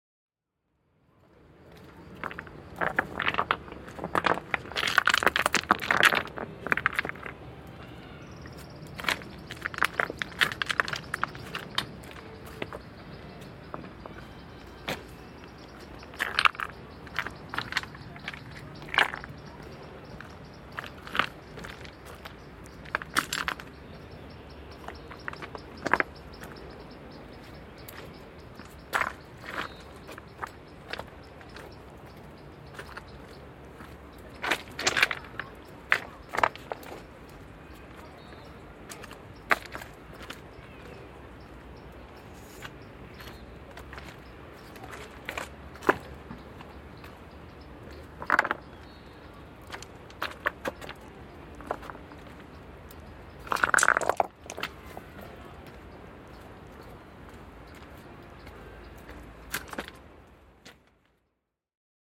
San Giovanni Fortress - Kotor city sound guide
Climbing up the rough cobblestones on my way to the highest viewpoint, I was able to hear the beautiful song of birds in the background with the panoramic sounds of the old town at large.